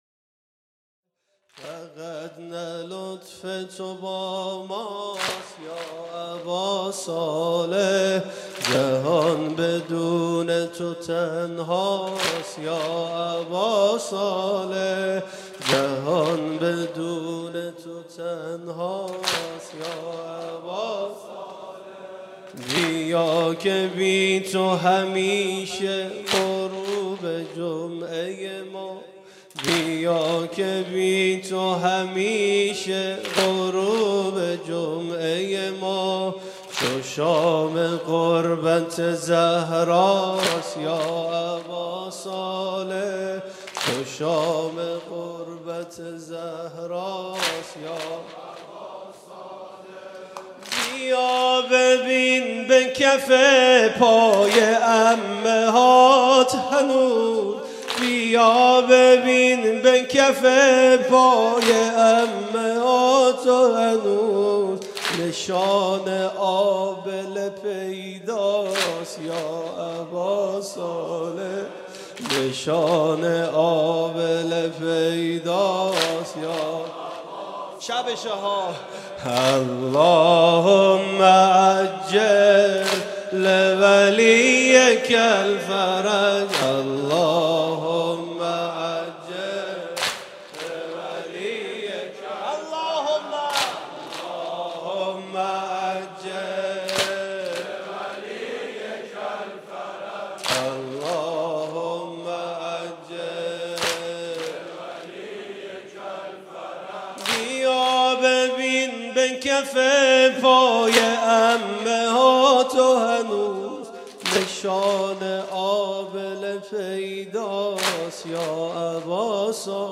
شب بیست و سوم رمضان 96 - هیئت شبان القاسم - یا أباصالح